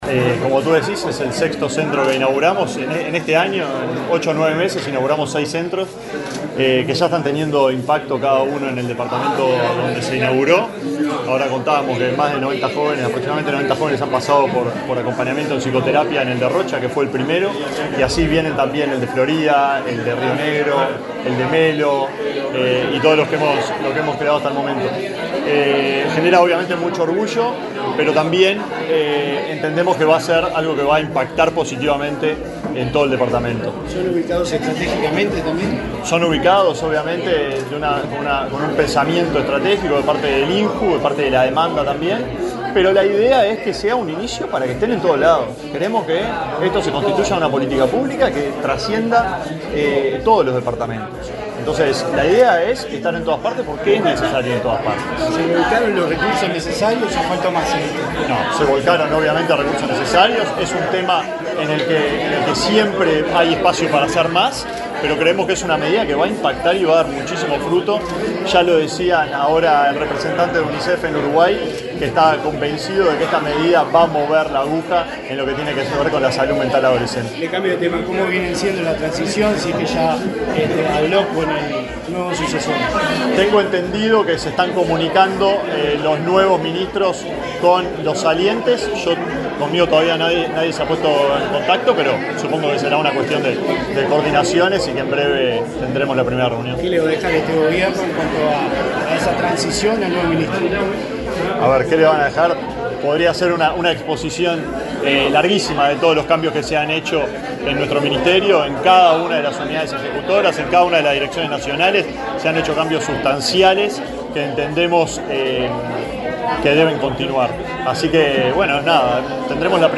Declaraciones del ministro de Desarrollo Social, Alejandro Sciarra
El ministro de Desarrollo Social, Alejandro Sciarra, dialogó con la prensa, luego de participar en la inauguración de un centro Ni Silencio Ni Tabú en